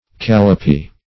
callipee - definition of callipee - synonyms, pronunciation, spelling from Free Dictionary Search Result for " callipee" : The Collaborative International Dictionary of English v.0.48: Callipee \Cal`li*pee"\, n. See Calipee .